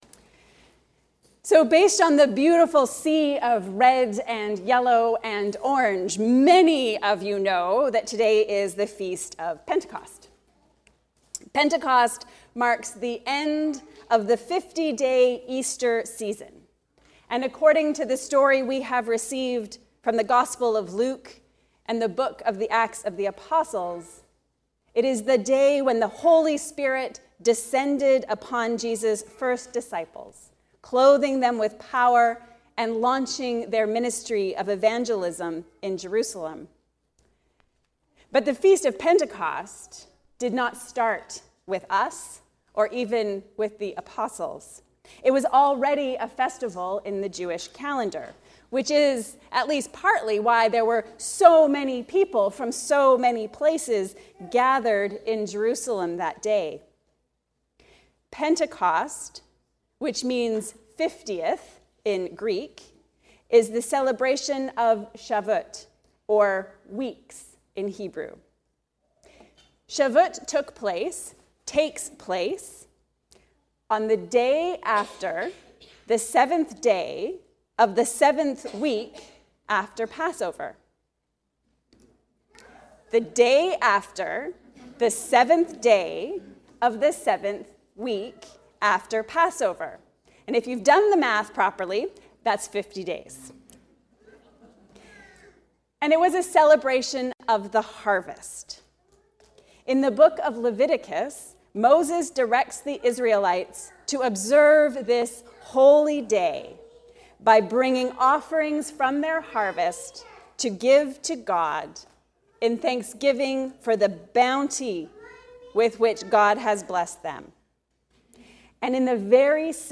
Blessed to be a Blessing. A Sermon for the Feast of Pentecost